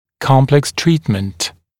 [‘kɔmpleks ‘triːtmənt][‘комплэкс ‘три:тмэнт]комплексное лечение; сложное лечение